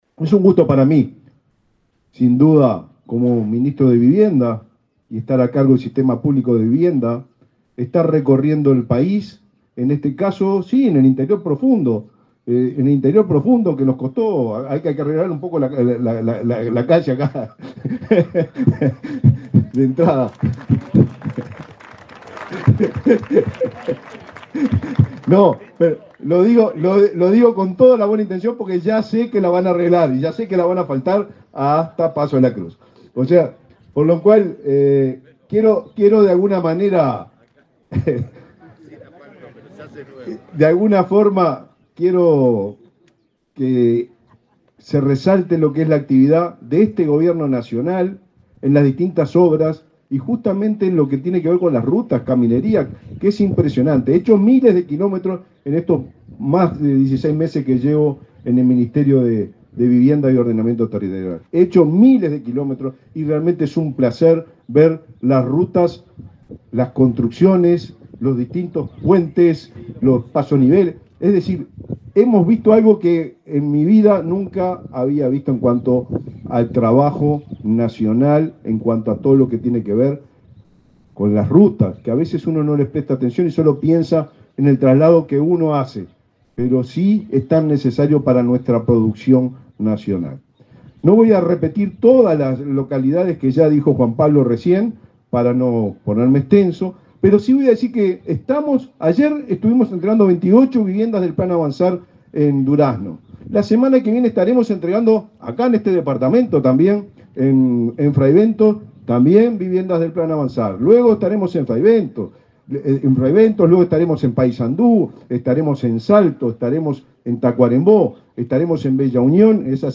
Palabras del ministro de Vivienda, Raúl Lozano
Palabras del ministro de Vivienda, Raúl Lozano 04/10/2024 Compartir Facebook X Copiar enlace WhatsApp LinkedIn El ministro de Vivienda, Raúl Lozano, participó en el acto de inauguración de 10 viviendas nucleadas en la comunidad rural de Paso de los Mellizos, en el departamento de Río Negro.